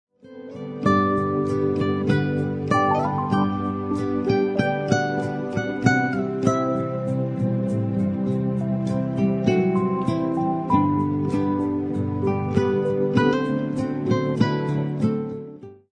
Instrumental Album of the Year